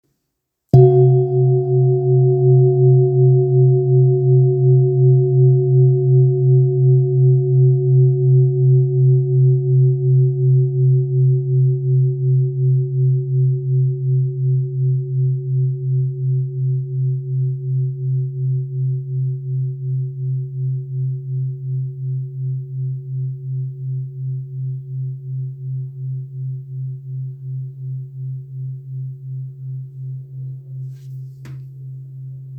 Kopre Singing Bowl, Buddhist Hand Beaten, Antique Finishing, Select Accessories, 19 by 19 cm,
Material Seven Bronze Metal
It is accessible both in high tone and low tone . Kopre Antique Singing Bowls is for the most part utilized for sound recuperating. In any case, it is likewise famous for enduring sounds.